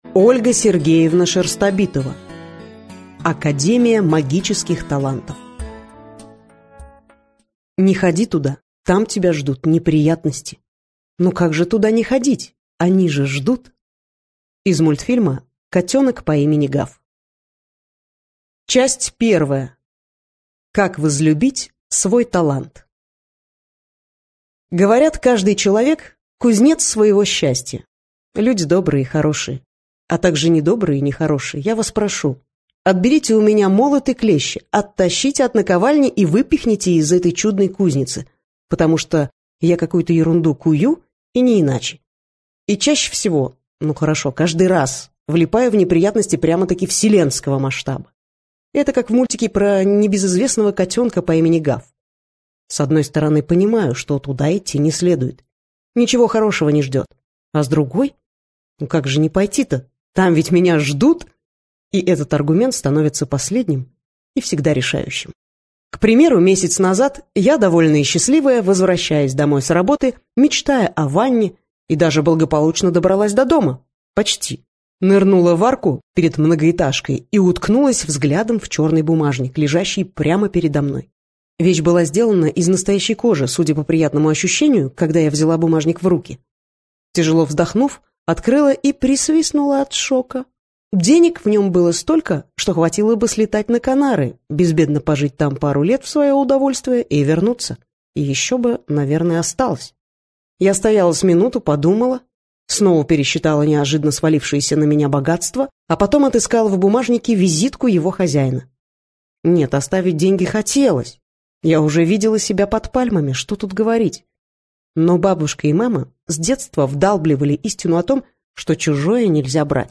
Аудиокнига Академия Магических Талантов - купить, скачать и слушать онлайн | КнигоПоиск